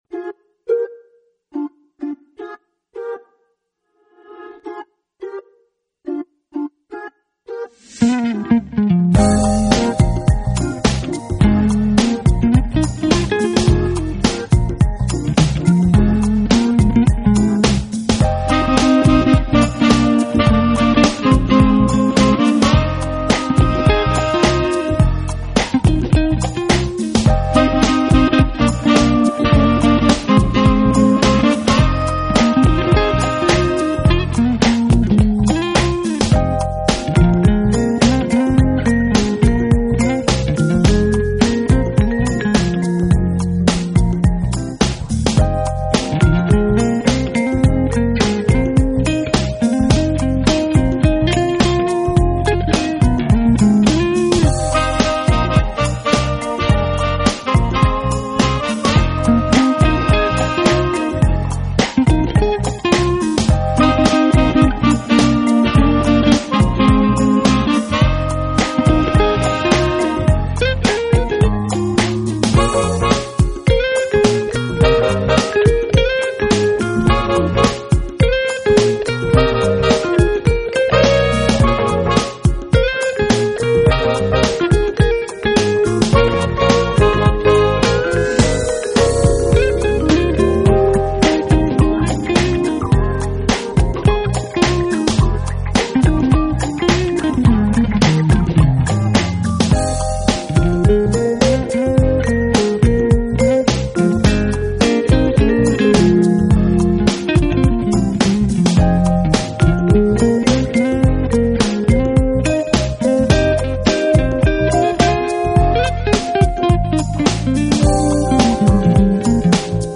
Contemporary Jazz / Crossover Jazz